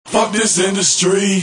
Tm8_Chant48.wav